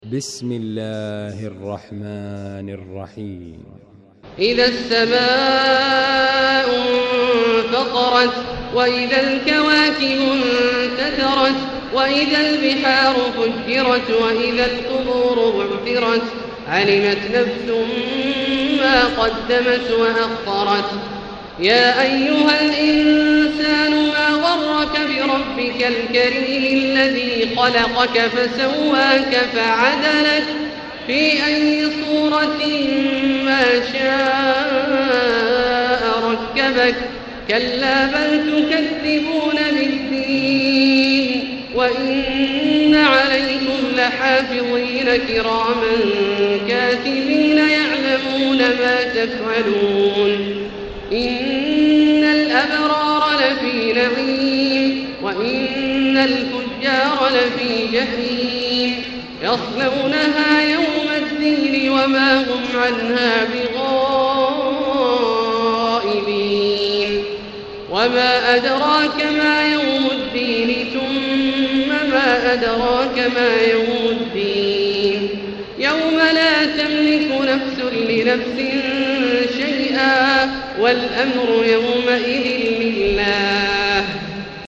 المكان: المسجد الحرام الشيخ: فضيلة الشيخ عبدالله الجهني فضيلة الشيخ عبدالله الجهني الانفطار